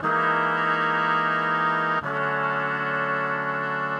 Index of /musicradar/gangster-sting-samples/120bpm Loops
GS_MuteHorn_120-E.wav